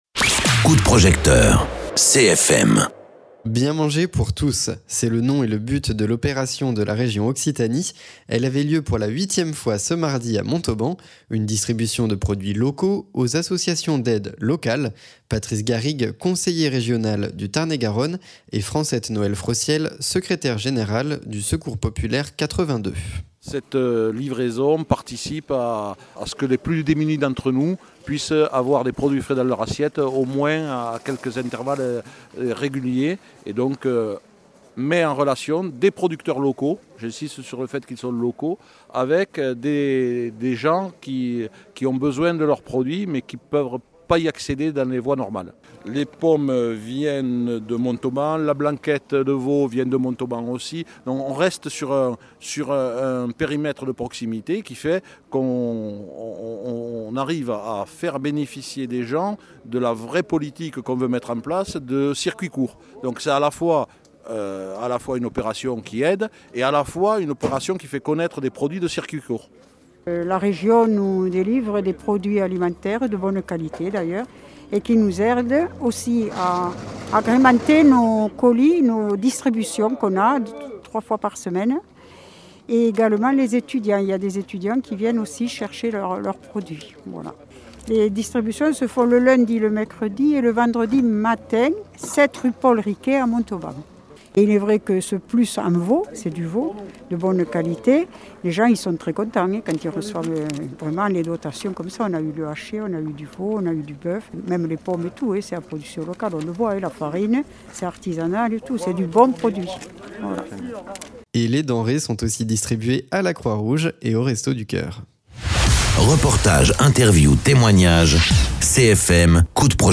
Invité(s) : Patrice Garrigues, conseiller régional du Tarn-et-Garonne